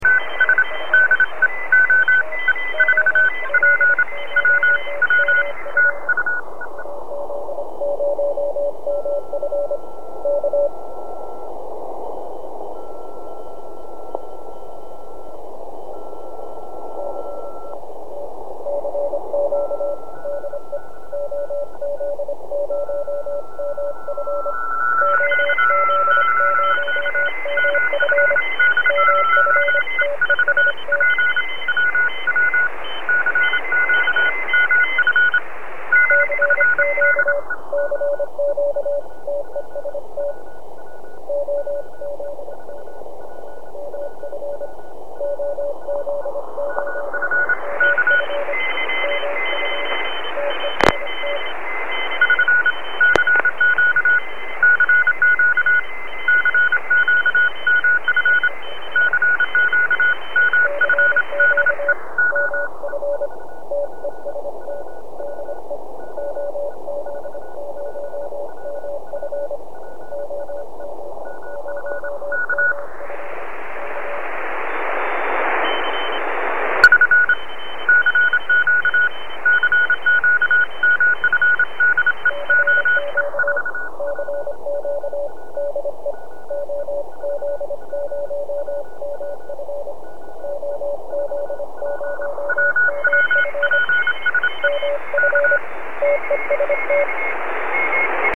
Rysunek zawiera schemat filtra a pliki dźwiękowe pokazują efekty jego działania przy odbiorze fonii i ssb.
schemat filtra   działanie w trybie cw
filtr_cw.mp3